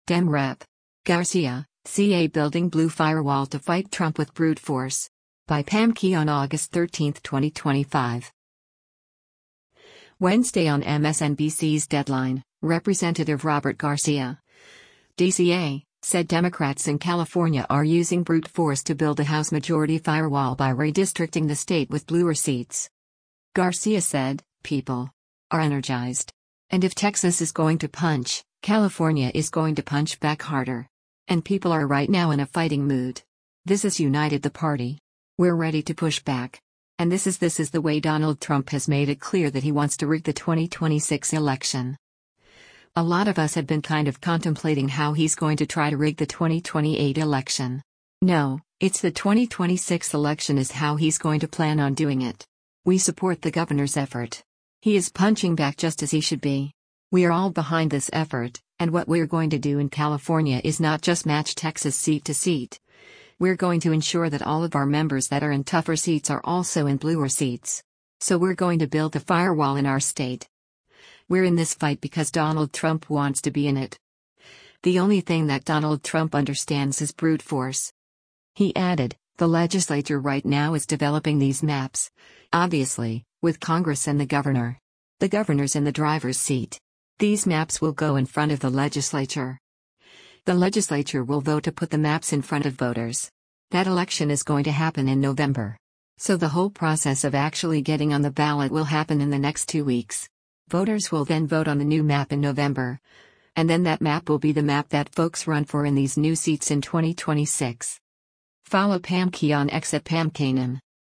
Wednesday on MSNBC’s “Deadline,” Rep. Robert Garcia (D-CA) said Democrats in California are using “brute force” to build a House majority “firewall” by redistricting the state with “bluer seats.”